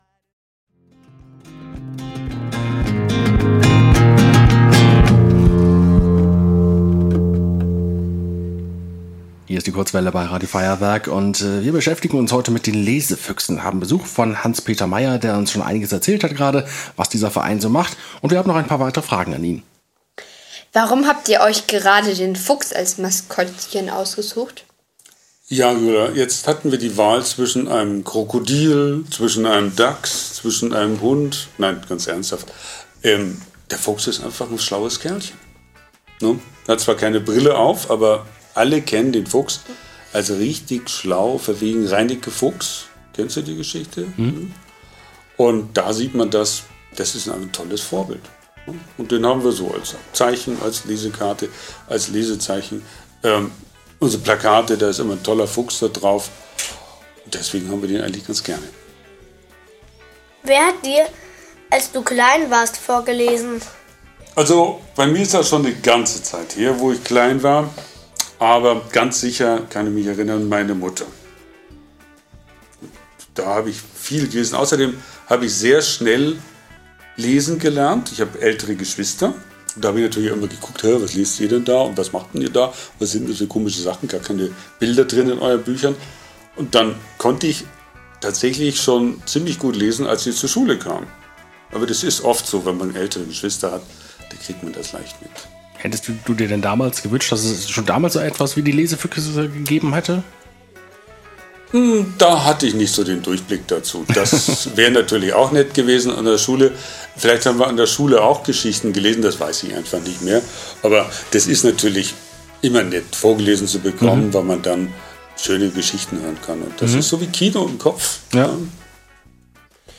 Abendlicher Besuch im Rundfunkstudio - Lesefüchse
Das Mikrofon, abgepolstert mit einer Schaumstoffunterlage.